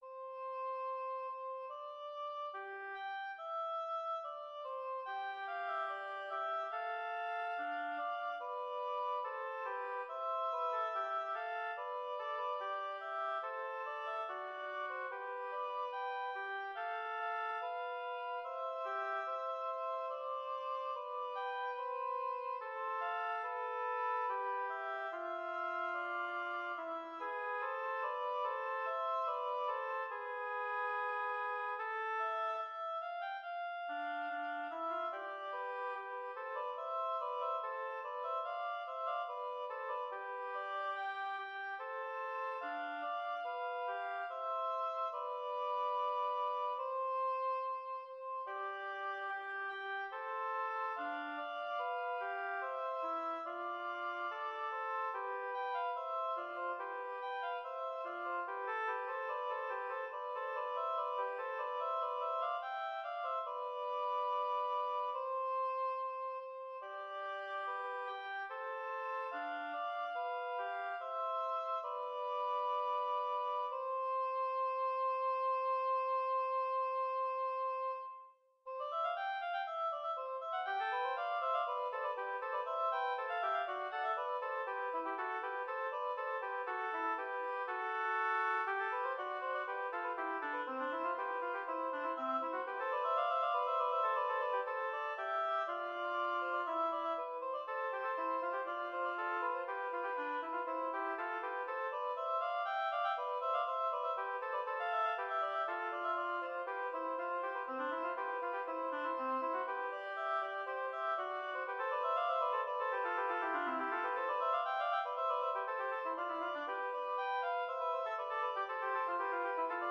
Intermediate oboe duet